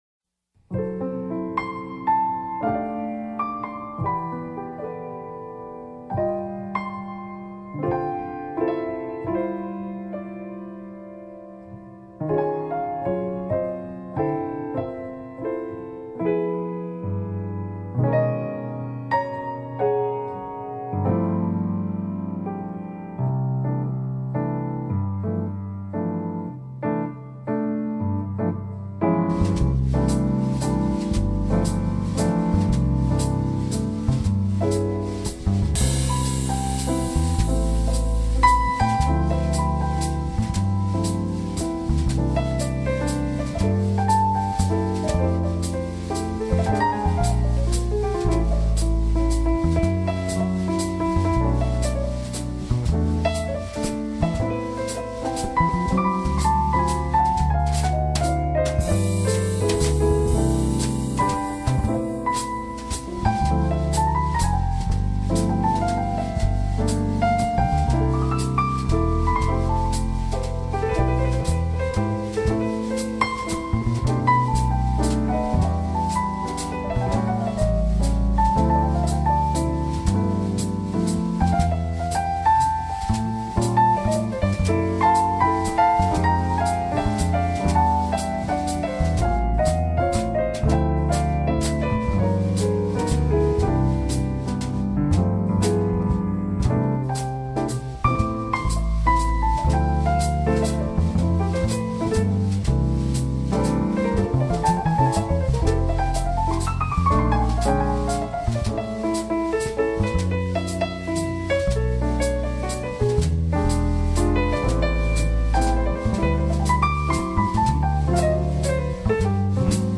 Эта композиция войдет в мой альбом музыки для джазового фортепианного трио.
Нет ничего режущего слух - ни в плане мелодии, ни в плане гармонии.
И по пространству (лично мне) хотелось бы чуть более компактного звучания.